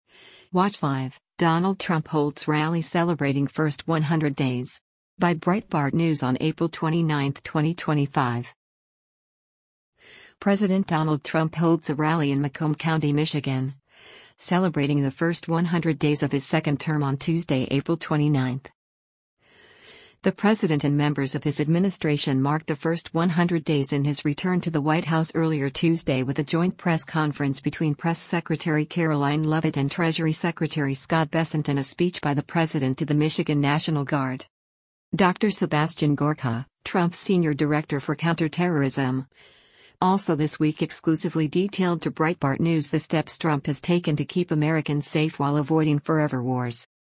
President Donald Trump holds a rally in Macomb County, Michigan, celebrating the first 100 days of his second term on Tuesday, April 29.